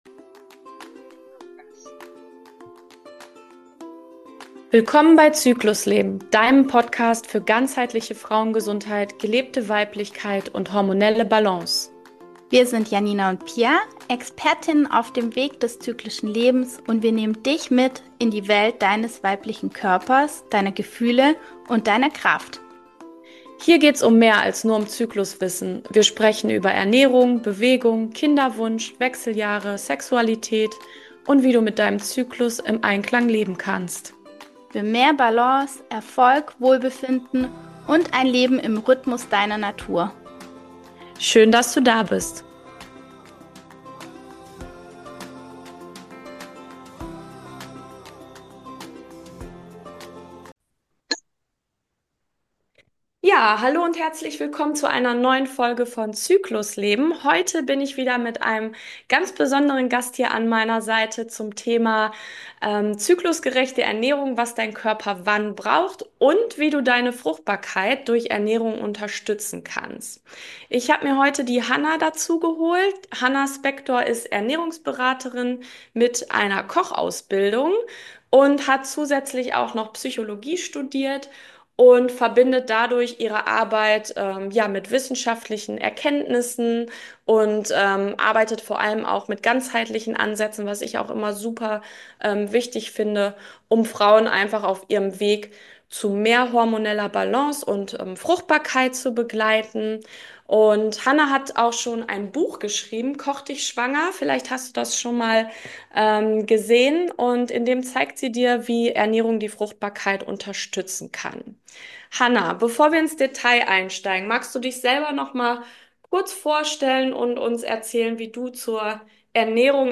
Ein inspirierendes Gespräch für alle Frauen mit Kinderwunsch oder dem Wunsch nach hormoneller Balance – voller Wissen, Erfahrung und praktischer Tipps.